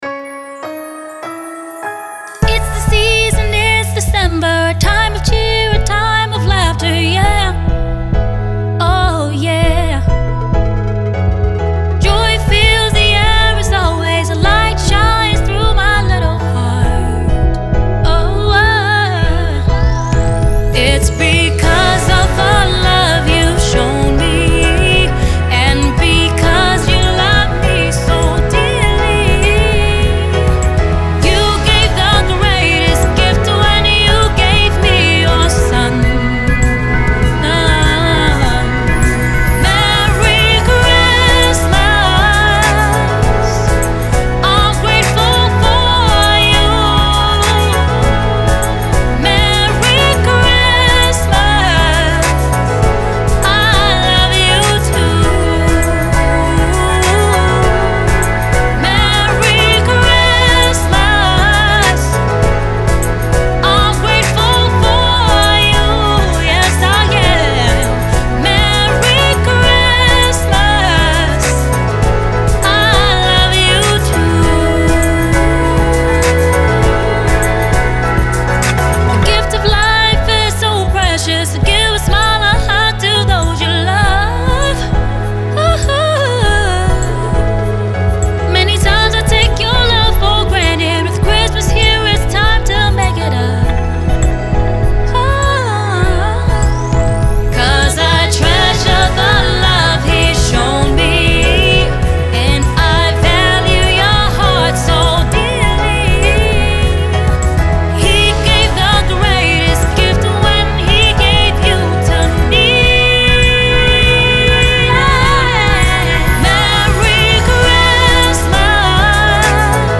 melodious track